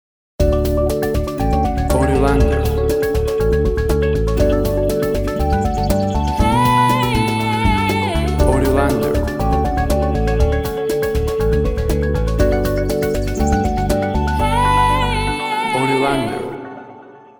Electronic sounds with warm nuances of vocals and harmonies.
Tempo (BPM) 120